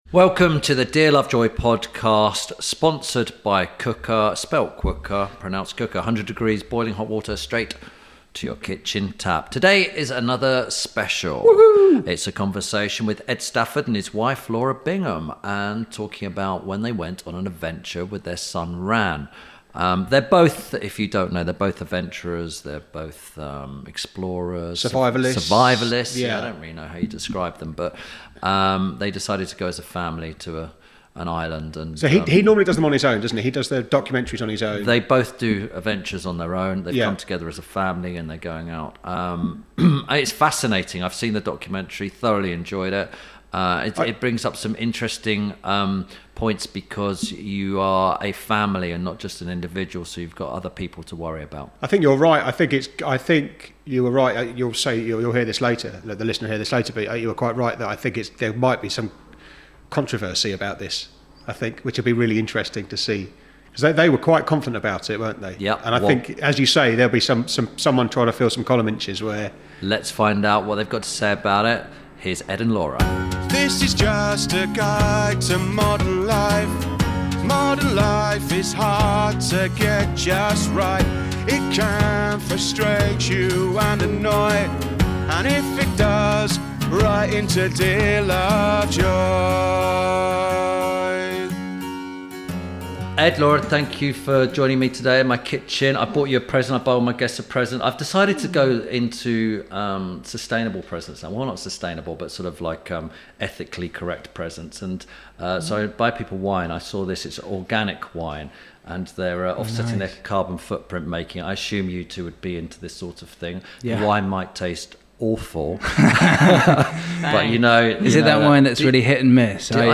Ep. 142 – ED STAFFORD & LAURA BINGHAM/FAMILY SURVIVAL - A Conversation With… – INTERVIEW SPECIAL
This week Tim Lovejoy talks to adventurers and survivalists Ed Stafford and Laura Bingham. Whilst discussing their new documentary ‘Man, Woman, Child, Wild’, they talk about taking their twenty–month old boy to survive on an island with only each other.